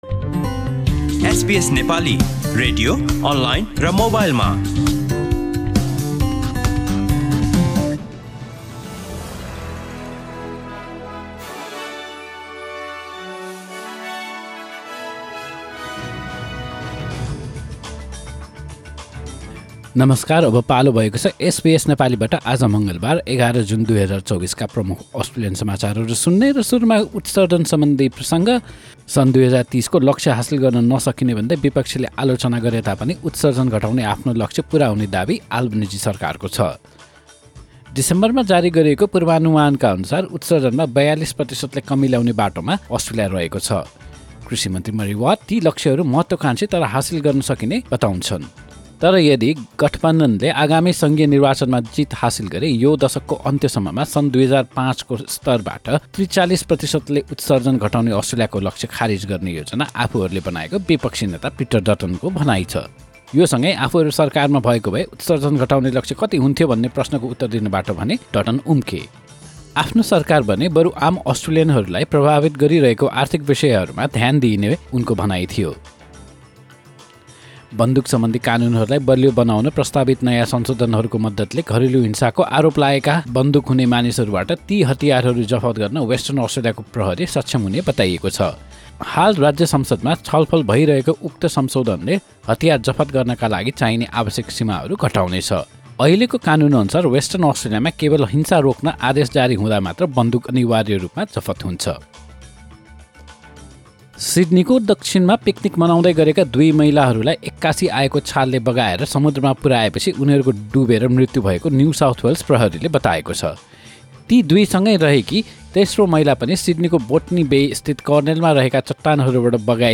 SBS Nepali Australian News Headlines: Tuesday, 11 June 2024